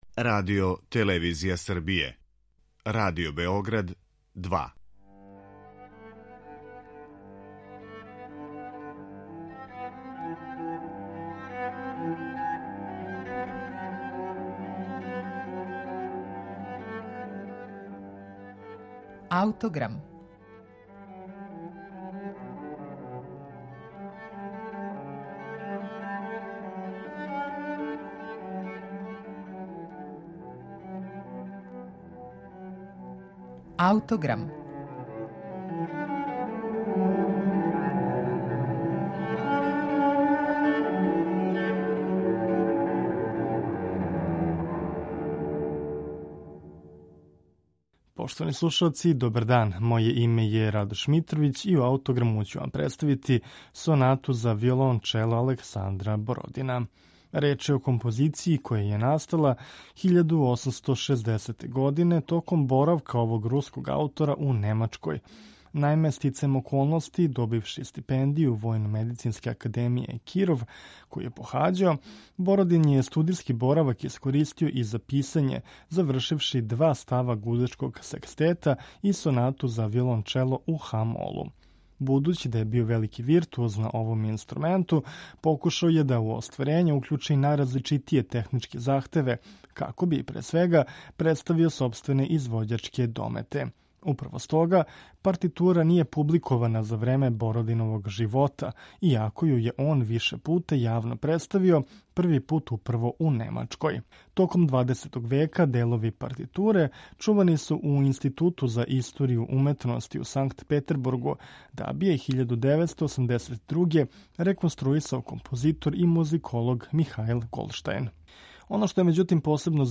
Виолончело је био омиљени инструмент руског композитора Александра Бородина.